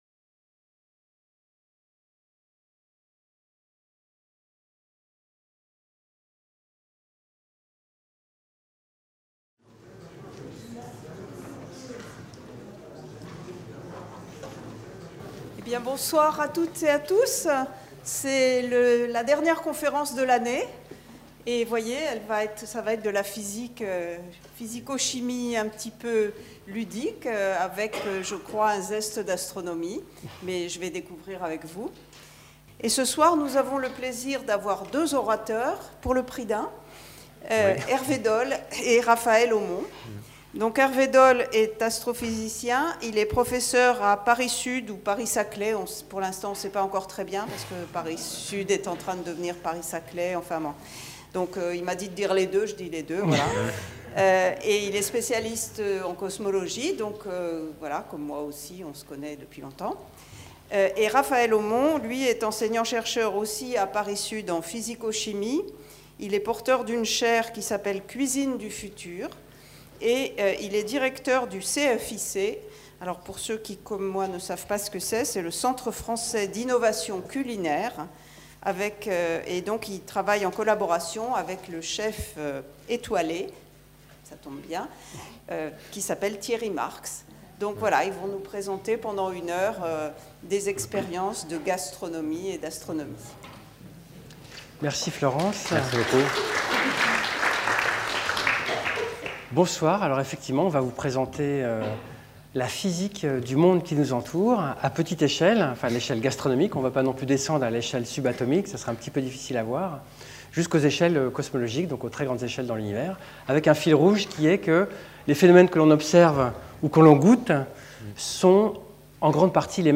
Deux enseignant-chercheurs de l'université Paris-Sud - l'un physico-chimiste, l'autre astrophysicien - dialoguent et échangent de manière décontractée et ludique (avec des petites expériences en direct) autour de grandes questions concernant l'alimentation de l'humanité, l'origine de l'univers, et l'universalité des lois de la physique.